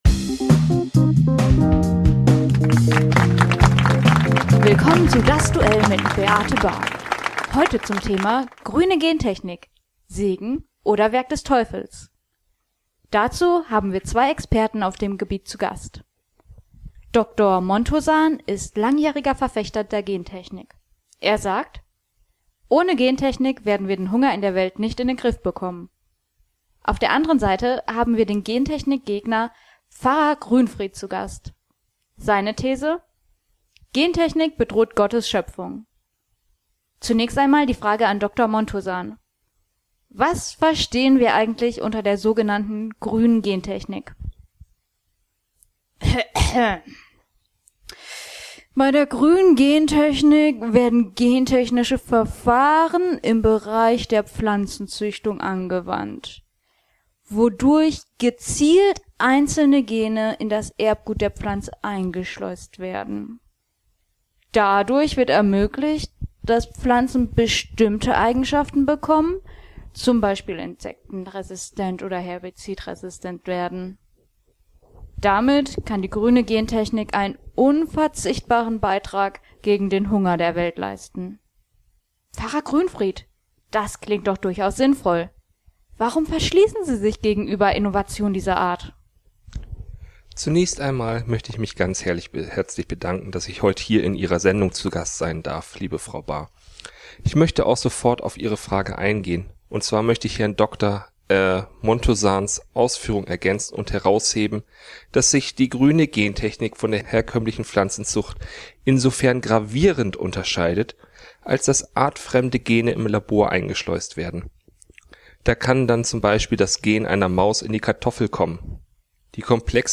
Hörspiel Das Duell.mp3